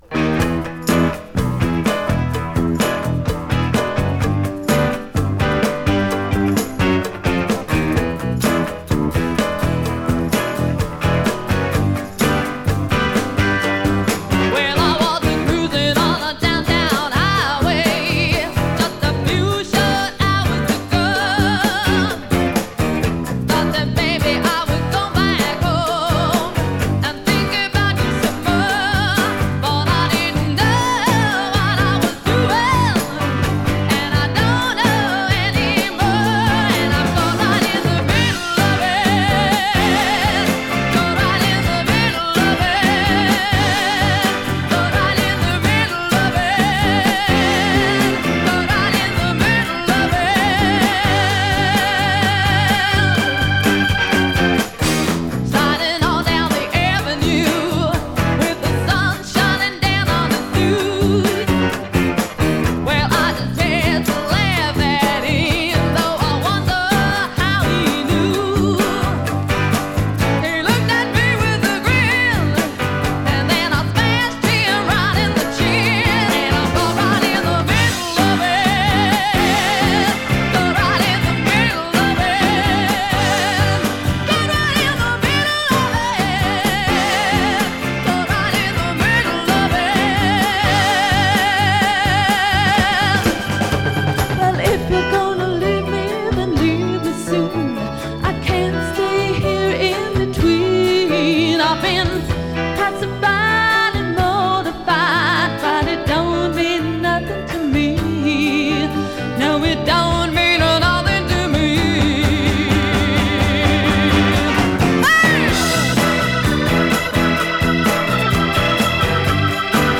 Жанр: Pop Rock, Folk Rock, Funk / Soul, Vocal